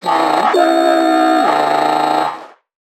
NPC_Creatures_Vocalisations_Infected [73].wav